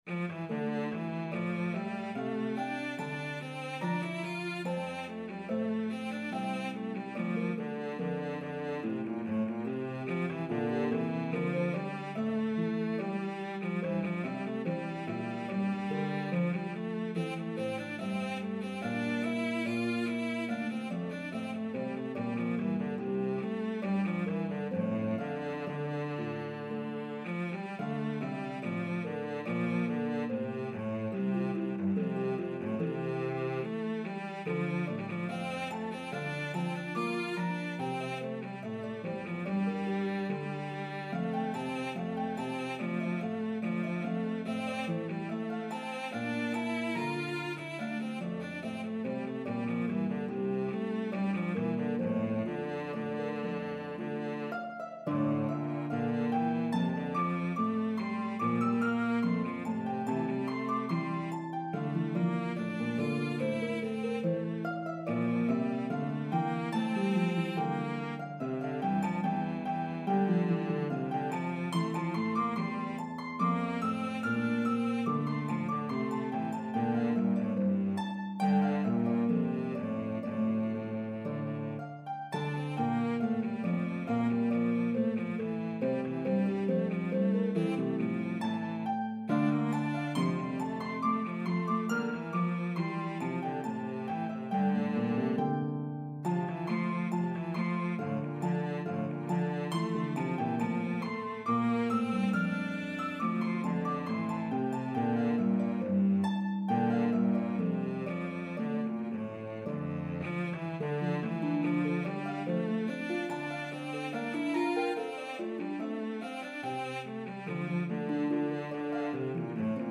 This beautiful Aeolian melody has a debated history.